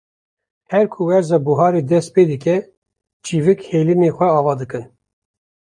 /t͡ʃɪˈviːk/